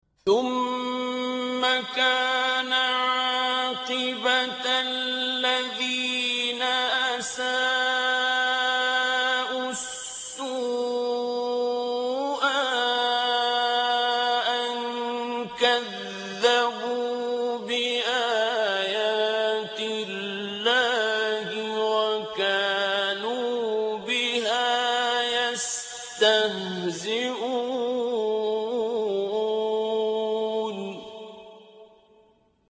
صوت | تلاوت آیه 10«سوره روم» با صوت قاریان شهیر
تلاوت آیه 10 سوره روم با صوت عبدالباسط محمد عبدالصمد